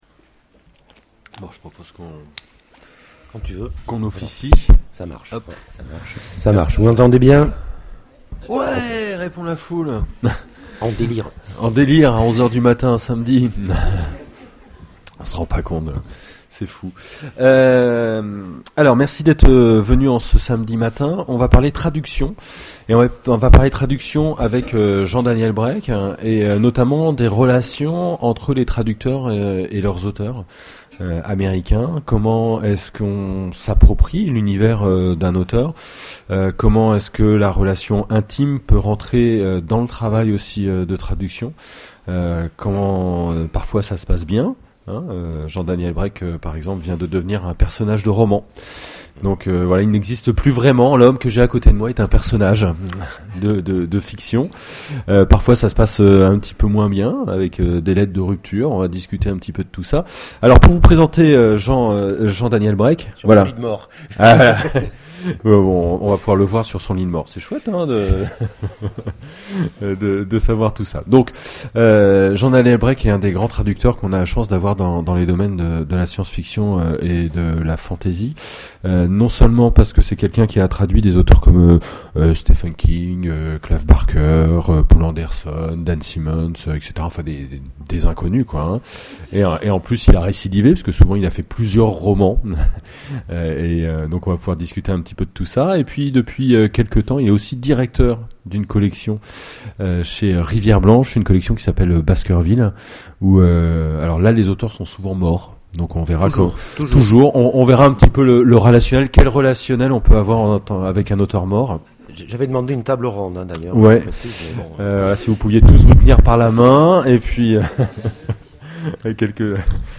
Imaginales 2013 : Conférence Dans l'antichambre des maîtres...